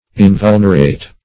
Invulnerate \In*vul"ner*ate\, a.